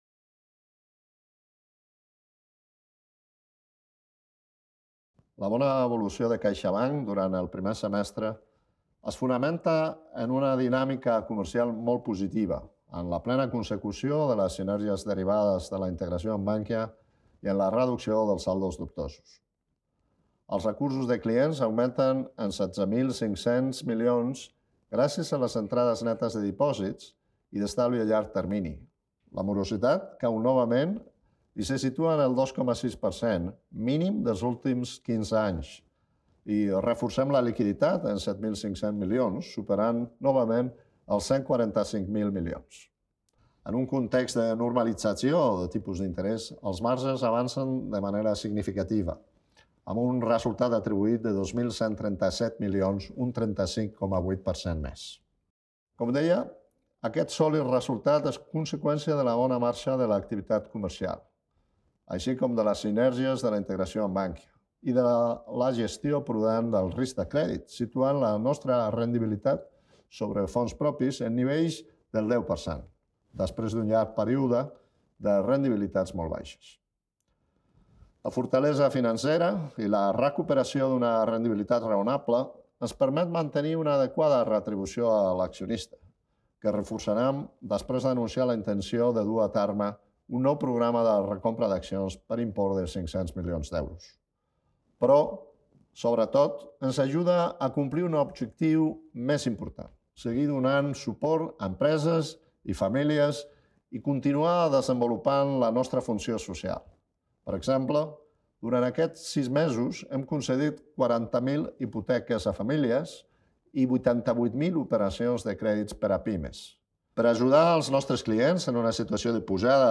Àudio del CEO de CaixaBank, Gonzalo Gortázar
Gonzalo Gortázar, conseller delegat de CaixaBank, en la presentació de resultats.